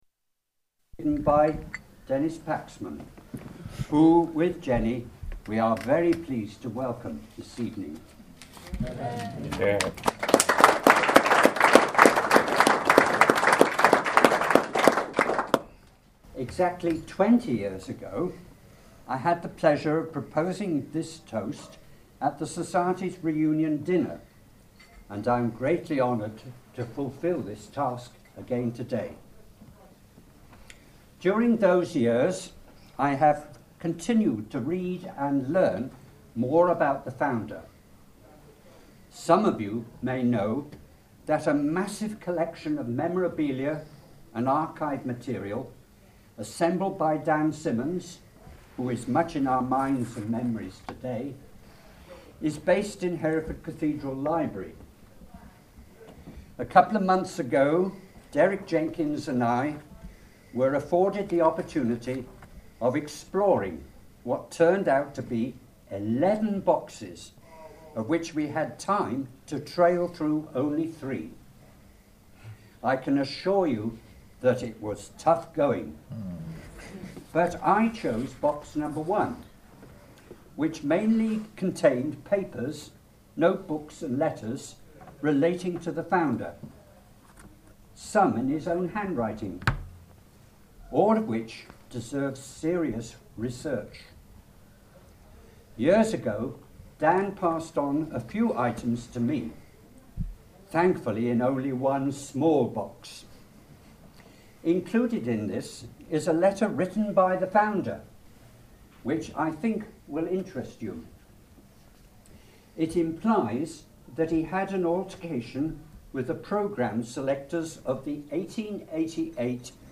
Reunion 2008 – St Michael's College Society, Tenbury, UK